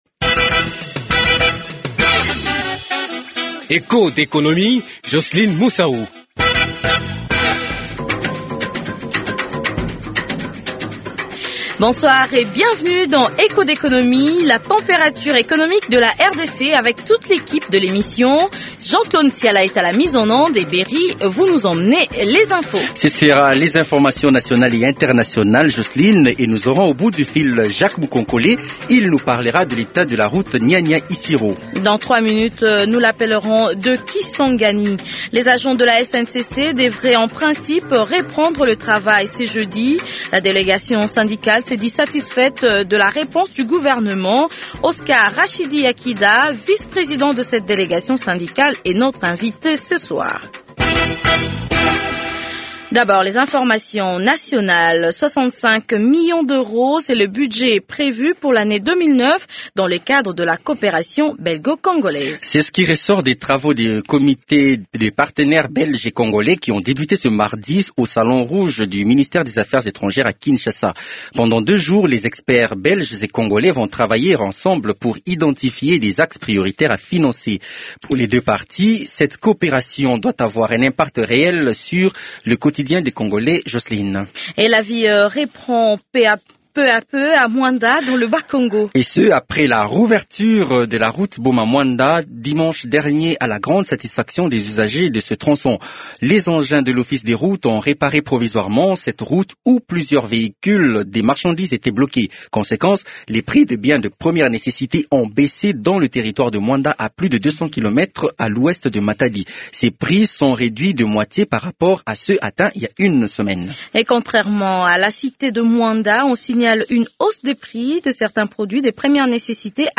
Uniformisation à Bunia des prix des bières de la Bralima aussi bien au niveau des dépositaires que des vendeurs détaillants. Reportage à suivre aussi dans cette émission.